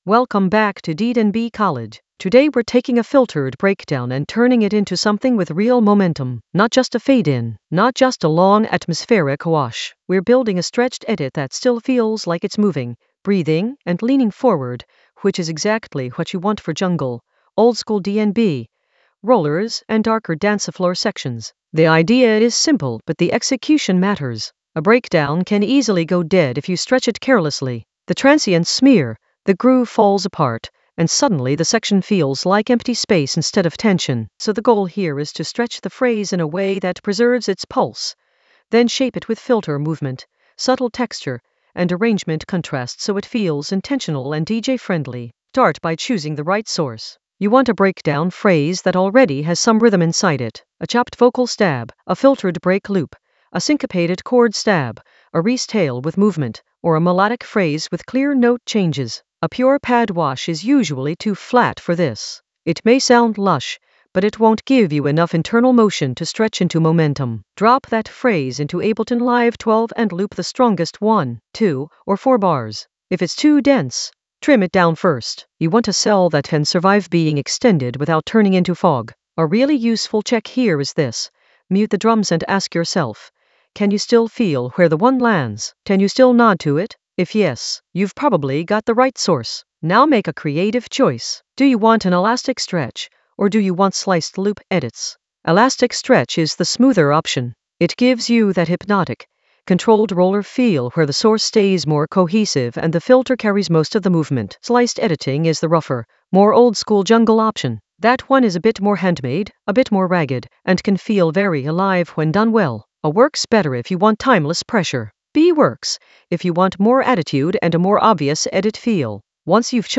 An AI-generated intermediate Ableton lesson focused on Stretch a filtered breakdown for timeless roller momentum in Ableton Live 12 for jungle oldskool DnB vibes in the Edits area of drum and bass production.
Narrated lesson audio
The voice track includes the tutorial plus extra teacher commentary.